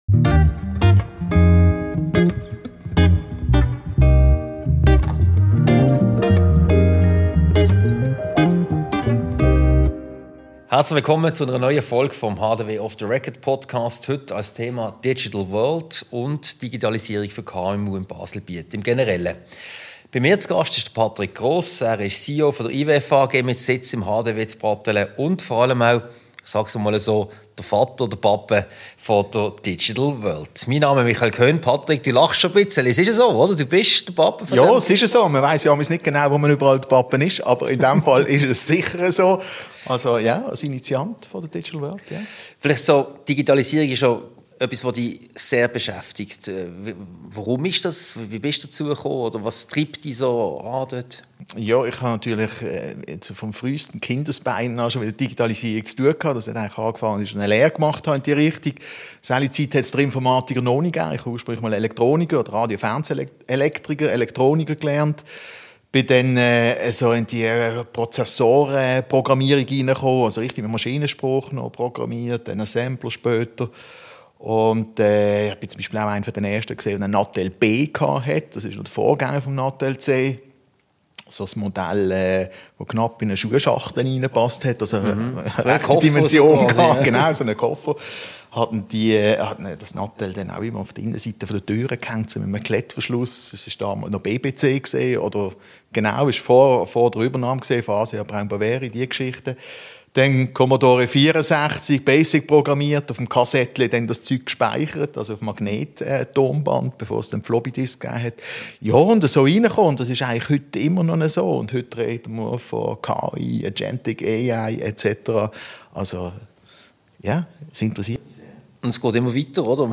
Ein Gespräch über die vielfältigen Aspekte und Angebote der Digital-World Conference vom 11. und 12. September, darüber, was die Berufsschau damit zu tun hat sowie über die digitalen Herausforderungen für die KMUs. Diese Podcast-Ausgabe wurde im Haus der Wirtschaft HDW aufgezeichnet.